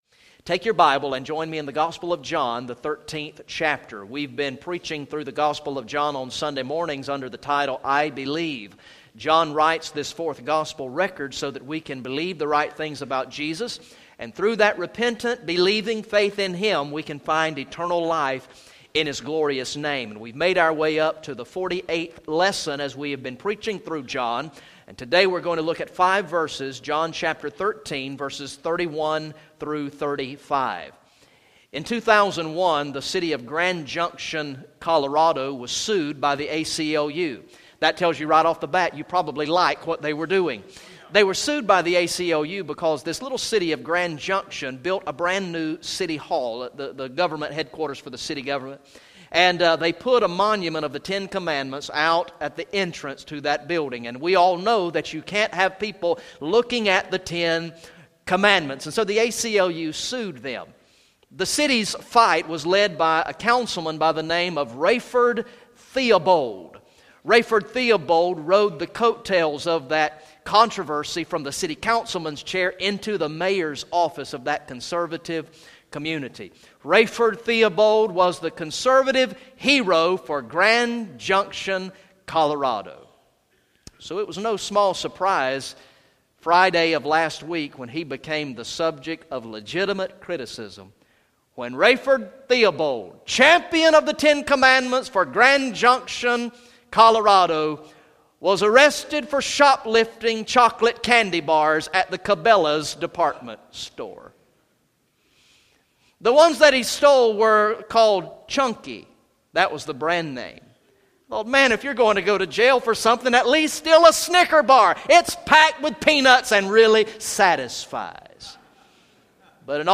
Message #48 from the sermon series through the gospel of John entitled "I Believe" Recorded in the morning worship service on Sunday, November 15, 2015